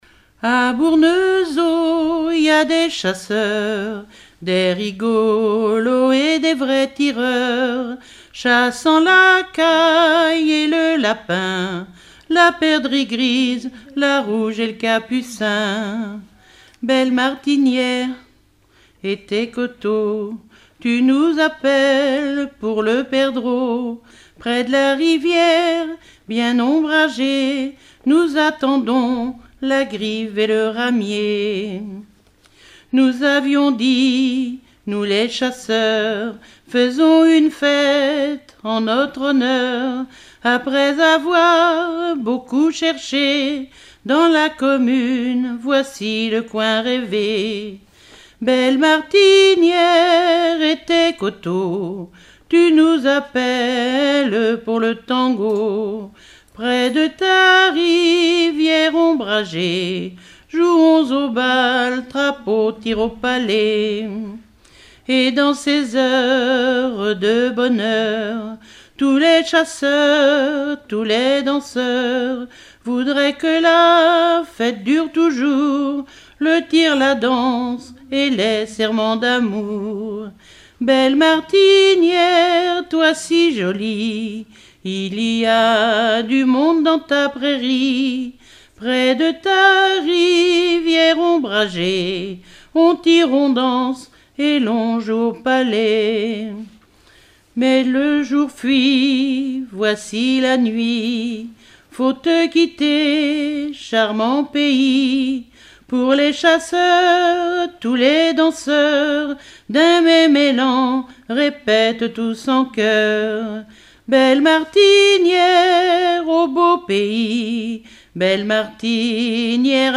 enregistrement d'un collectif lors d'un regroupement cantonal
Pièce musicale inédite